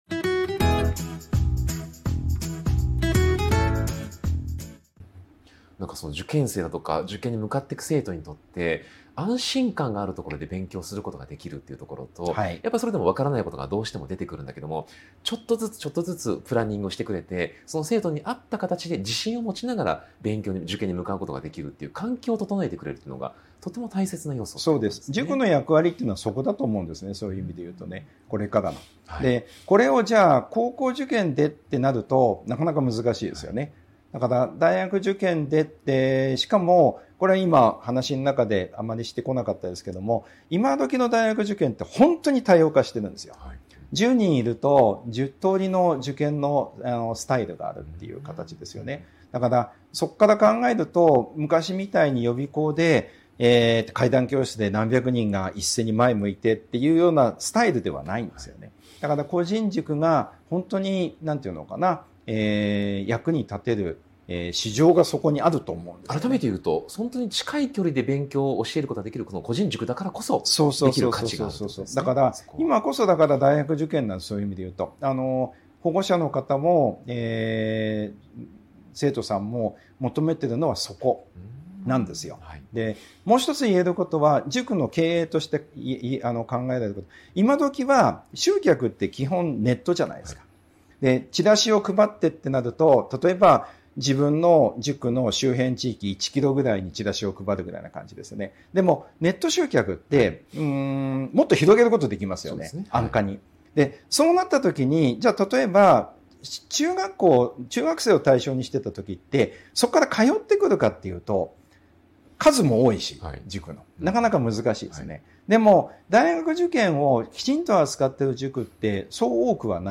【特別インタビュー】塾業界38年の現場から見える未来の学習塾経営とは 個人塾が生き残るための「縦展開」と「寄り